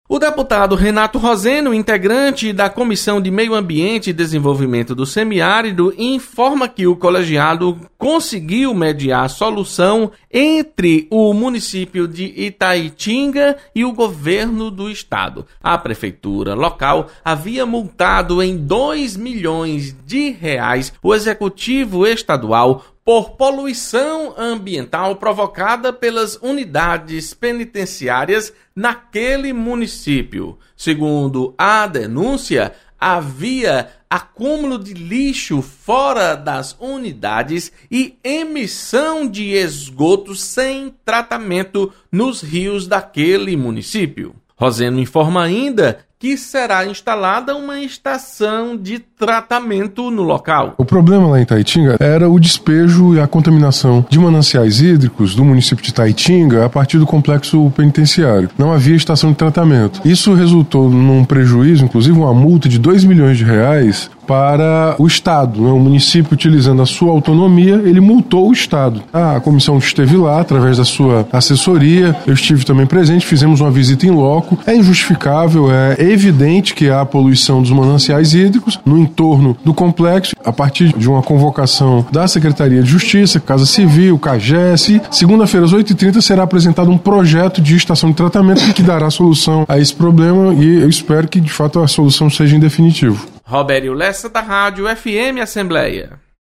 Comissão do Meio Ambiente e Desenvolvimento do Semi-Árido atua para mediar acordo entre município de Itaitinga e o Estado. Repórter